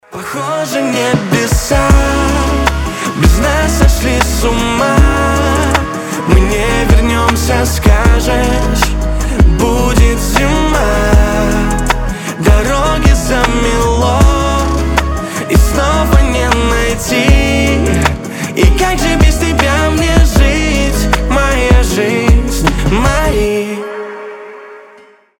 • Качество: 320, Stereo
красивые